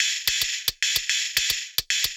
UHH_ElectroHatB_110-05.wav